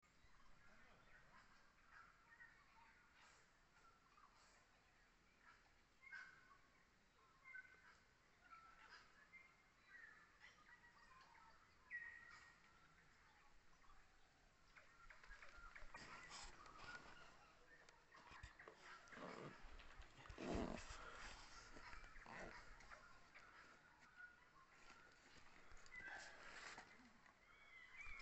The birds in the forest were just going off! This was recorded from the carpark in Tunnel Gully:
I’ve met alpacas, listened to birdsong, ridden through tunnels aver over bridges all within hours of home.